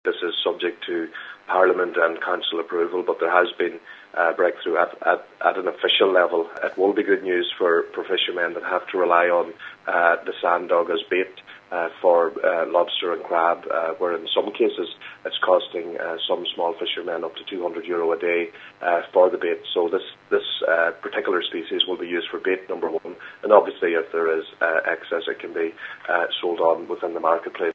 Deputy Joe Mc Hugh says the species, known locally as ‘sand dog’, is not edible to humans but is used extensively as bait.